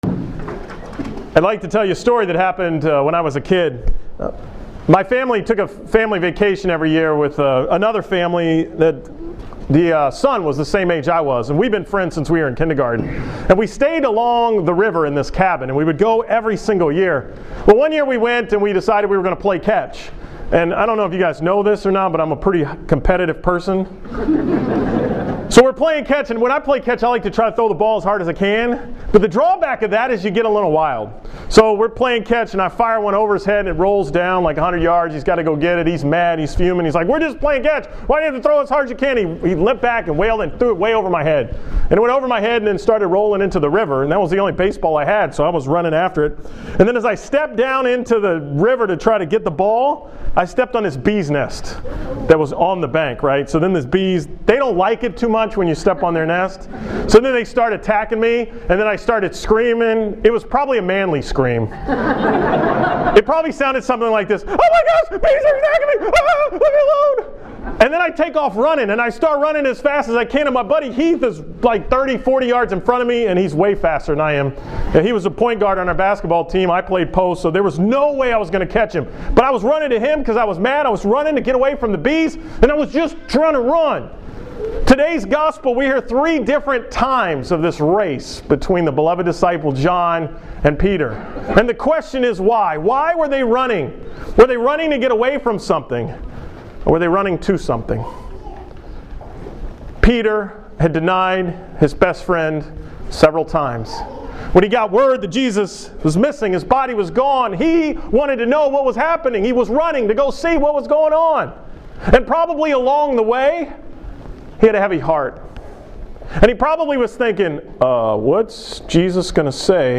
From Easter Sunday 11:15 Mass
Category: 2014 Homilies